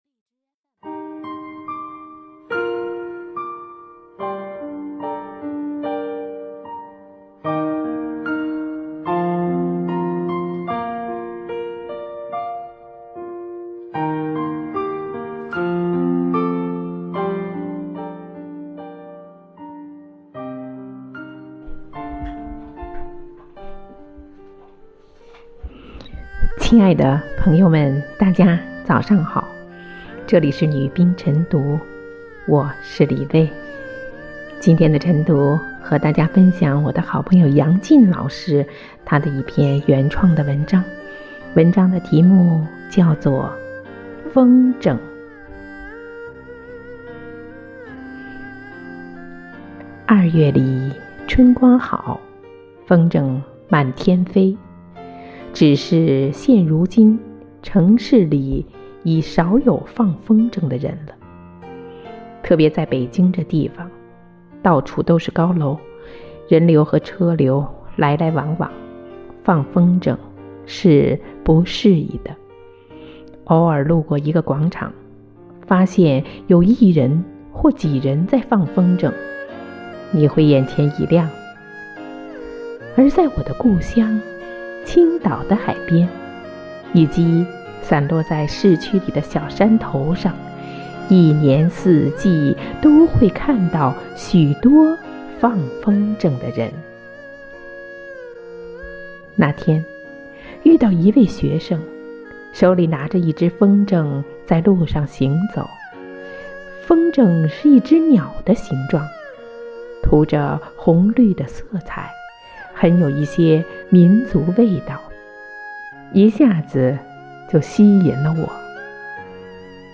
每日《女兵诵读》风筝